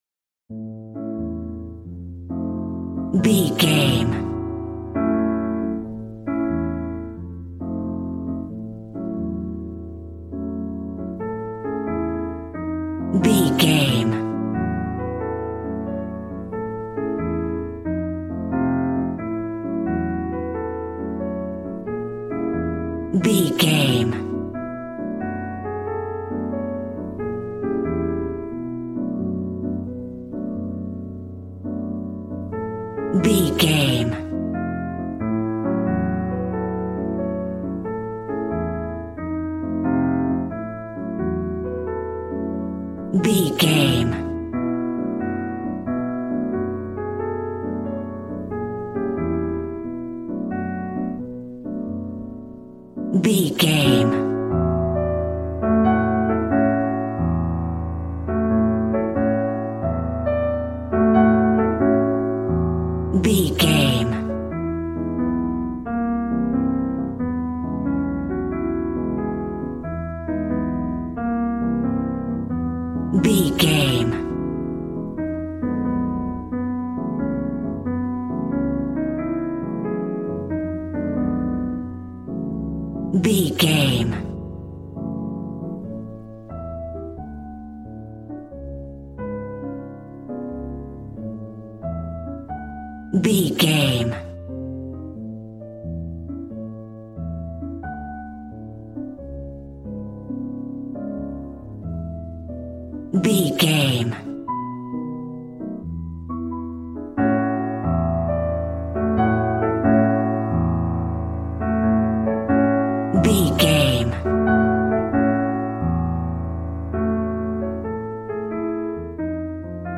Smooth jazz piano mixed with jazz bass and cool jazz drums.,
Ionian/Major
piano
drums